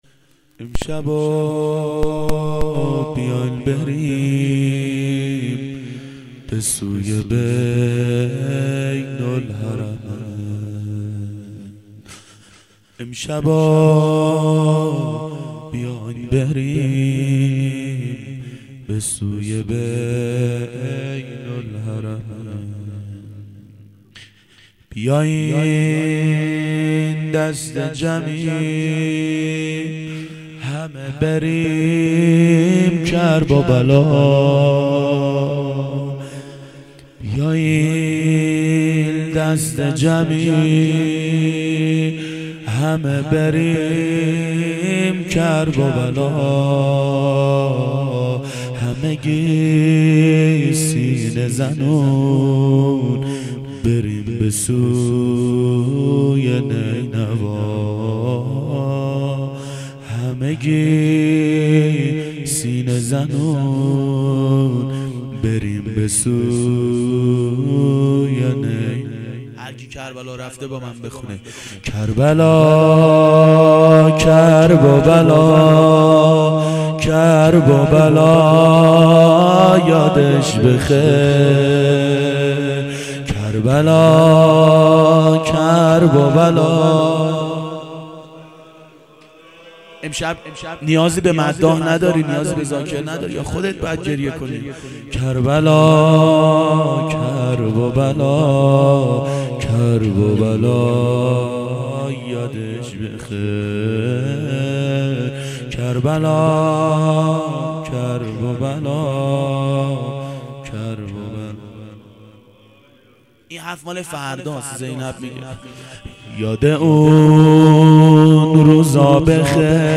شب عاشورا 1390 هیئت عاشقان اباالفضل علیه السلام